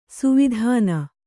♪ suvidhāna